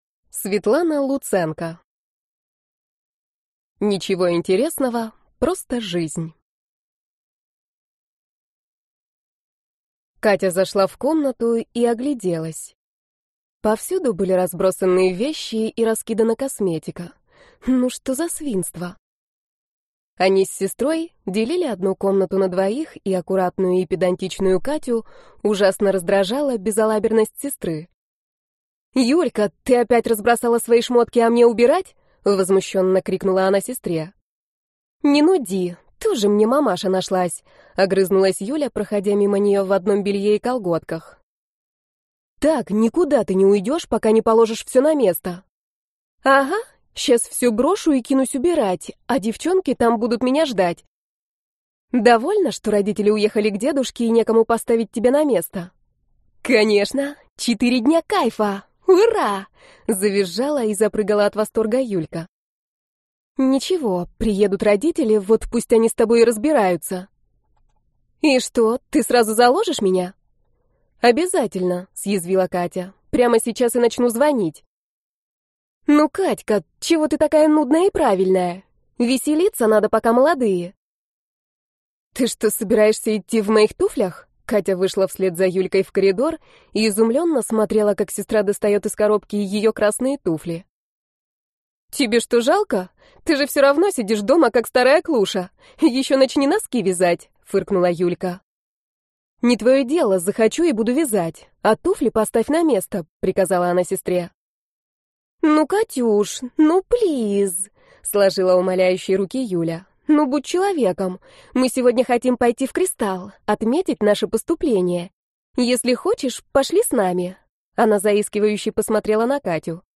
Аудиокнига Ничего интересного. Просто – жизнь | Библиотека аудиокниг